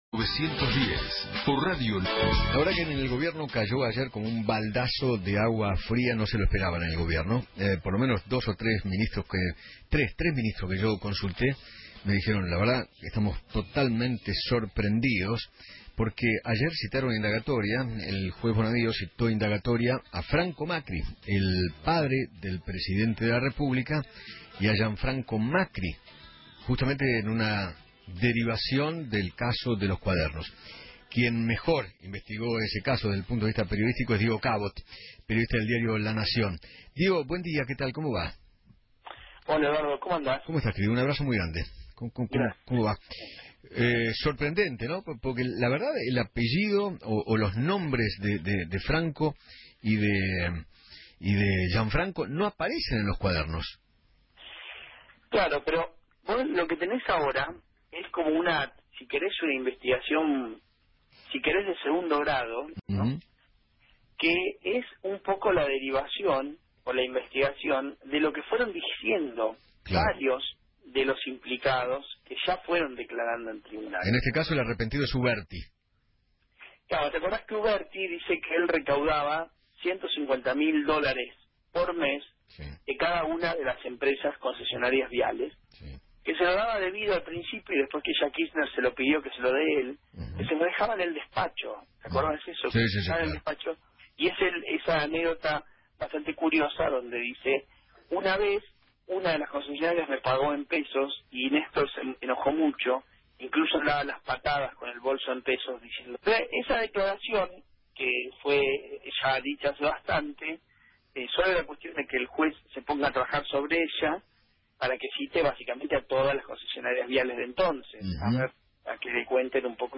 Diego Cabot, periodista de La Nación quién descubrió el escándalo de los cuadernos de las coimas, habló en Feinmann 910 y dijo que “Ahora comienza la investigación de segundo grado, que es un poco de la derivación de lo que fueron diciendo los implicados. Claudio Uberti dice que recaudaba de las empresas concesionarias viales, primero le daba el dinero a Julio De Vido y luego a Néstor Kirchner.”